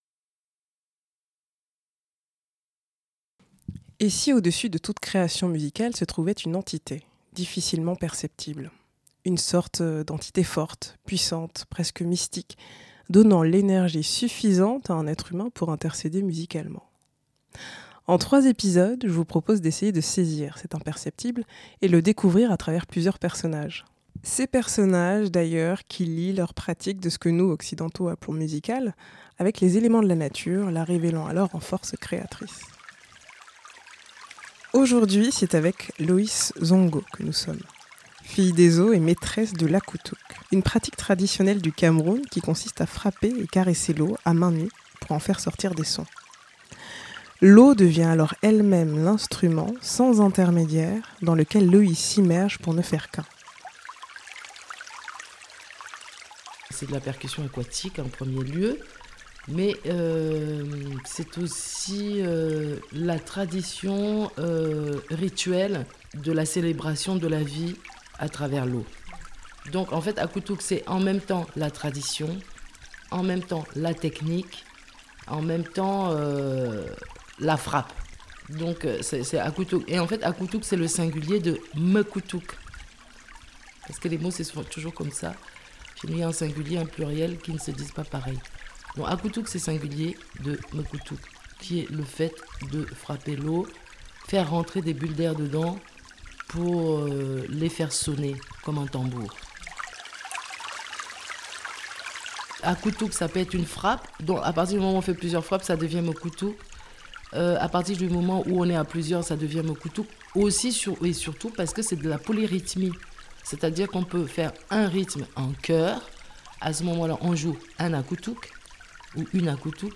Création sonore
Une pratique ancestrale féminine du Cameroun où l'on fait émerger des sons par le biais de frappes rythmées. Symbiose divine, ondulations puissantes : il n'y pas d'intérmédiaire dans l'Akutuk .